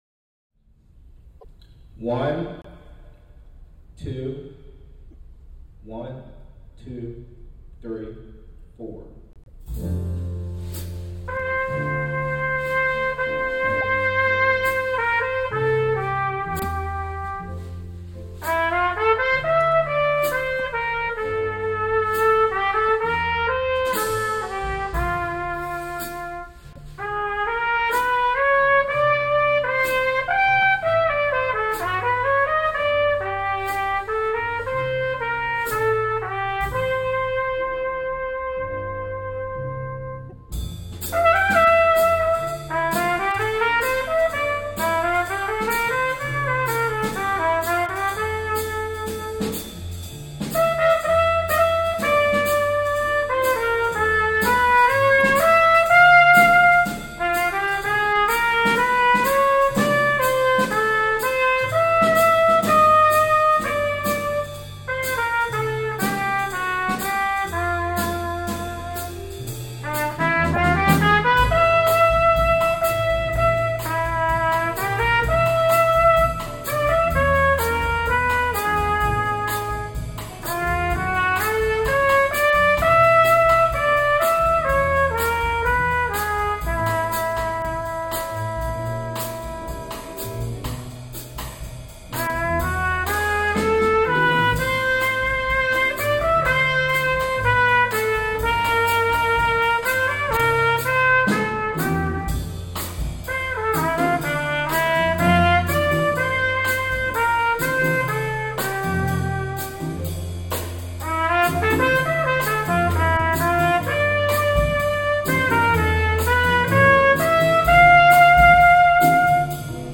Trumpet
Ballad Etude
Performance with Accompaniment
set-1-ballad-with-bkgd.m4a